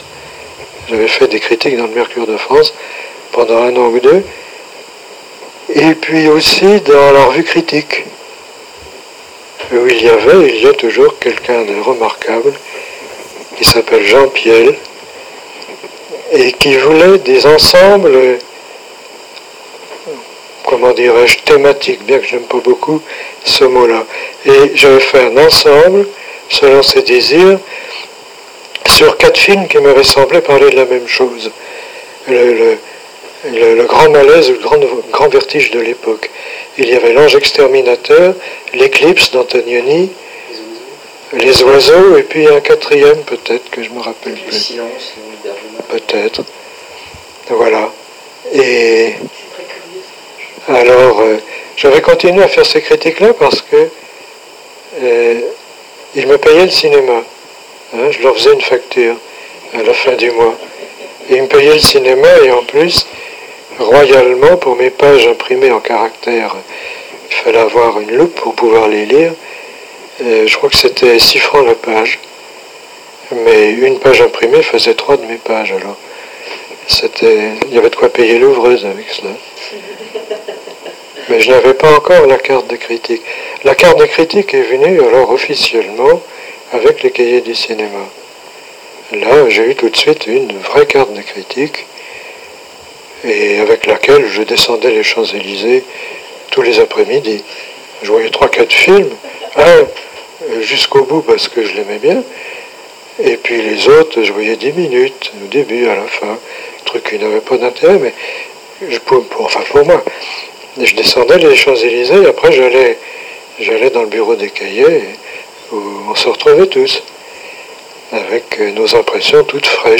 Entretien avec Claude Ollier